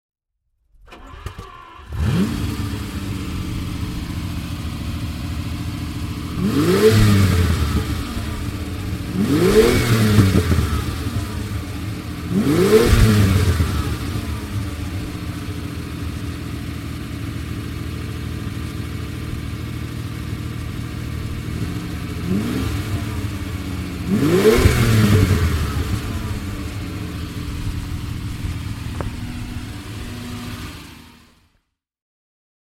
Ferrari 330 GT (1967) - Starten und Leerlauf